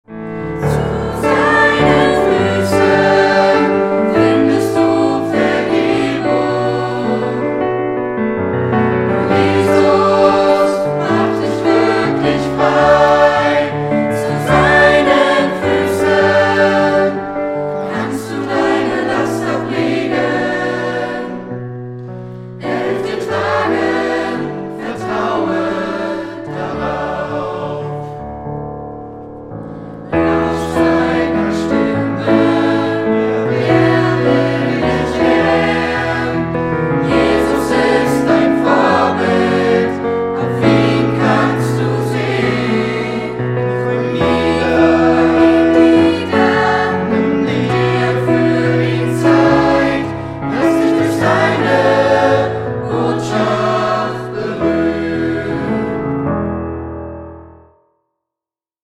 Notation: SATB+Solo
Tonart: D,E,F
Taktart: 9/8
Tempo: 100 bpm
Parts: 5 Verse, 2 Refrains, Zwischenspiel, Bridge, Coda
Noten, Noten (Chorsatz)